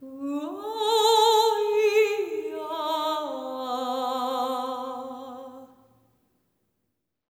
ETHEREAL04-R.wav